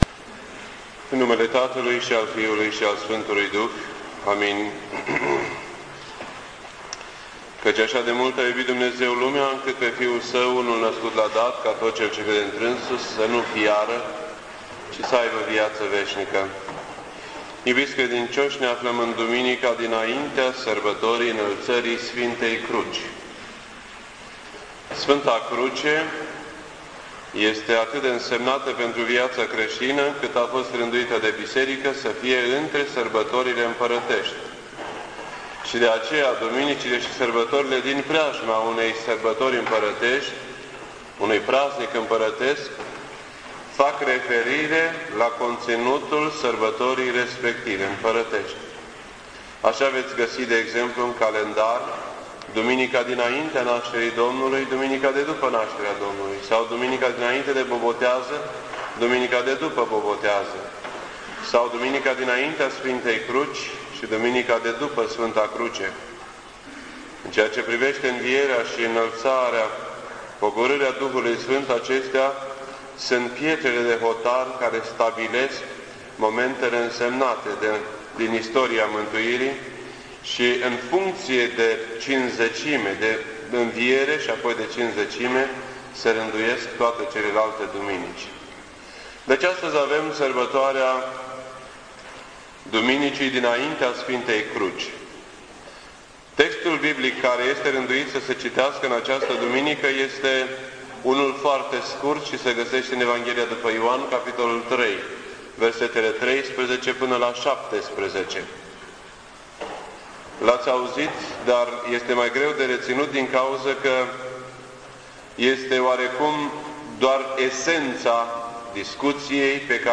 This entry was posted on Sunday, September 9th, 2007 at 10:08 AM and is filed under Predici ortodoxe in format audio.